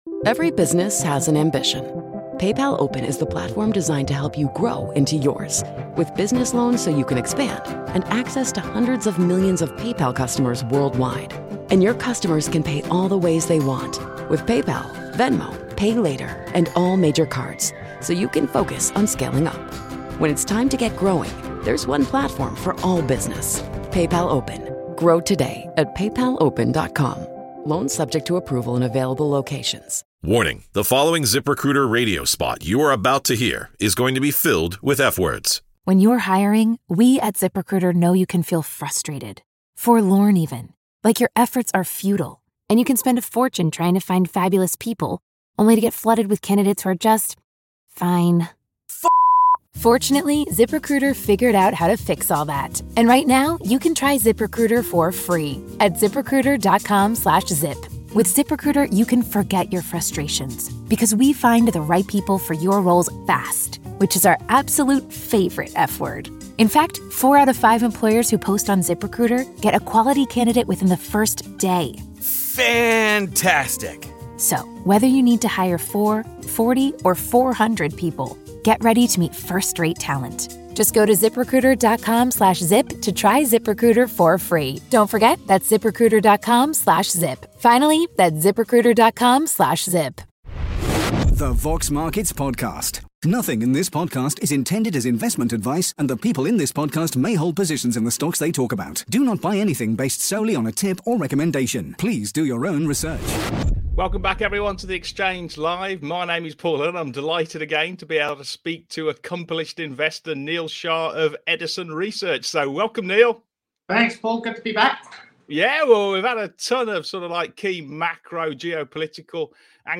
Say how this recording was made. On today's live Exchange show